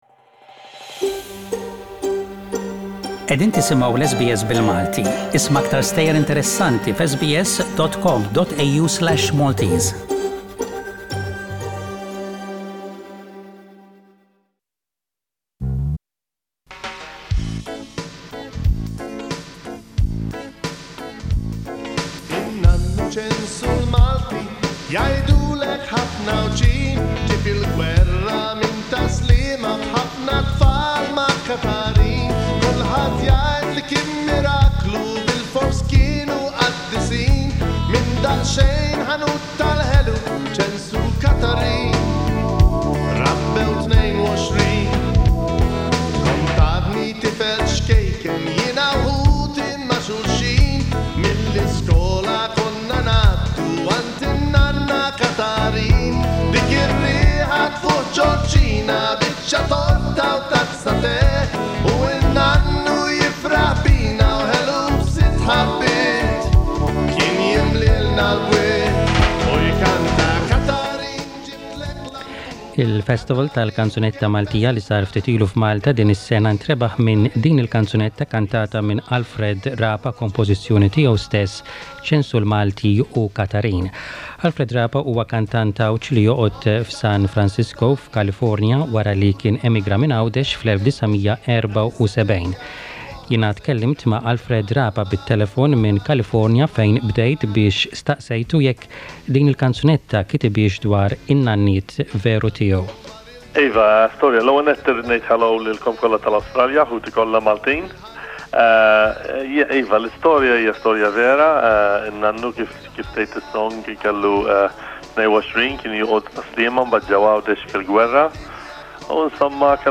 1996 Interview